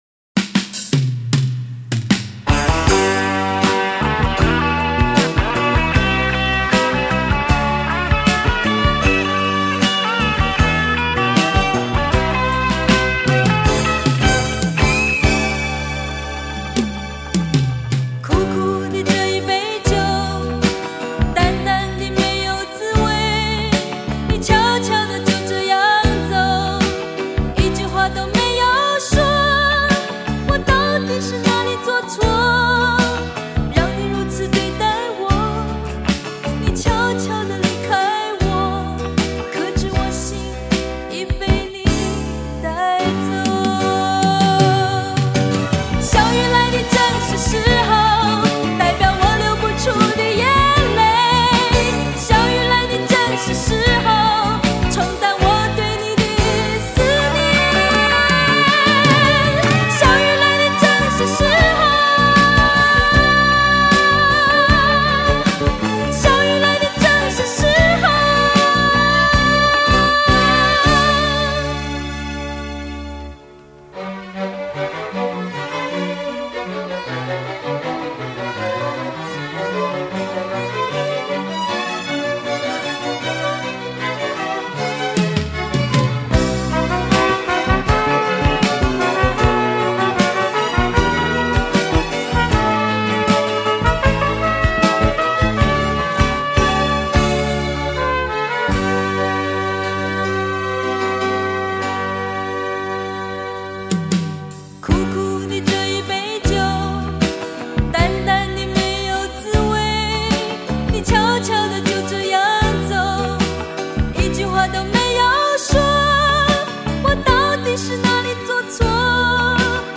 专辑重新“烫金”--母带remasterin数位化处理 重现原曲原音